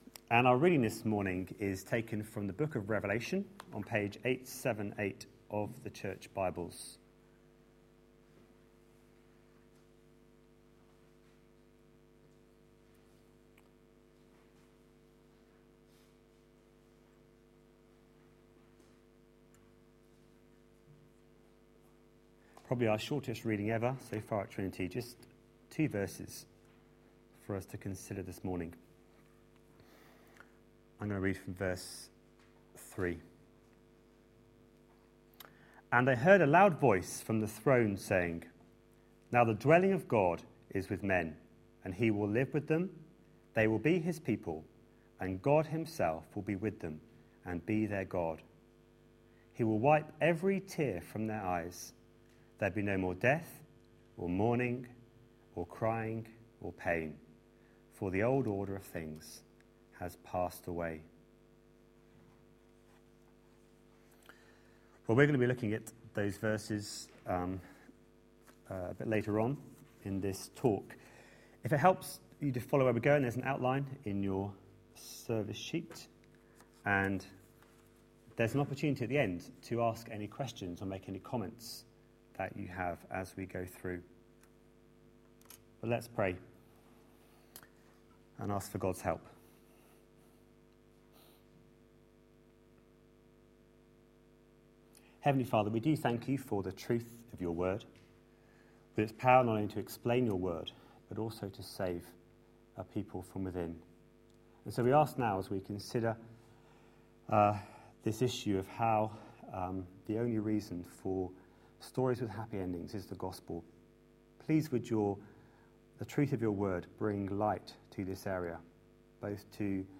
A sermon preached on 12th January, 2014, as part of our The gospel is the reason series.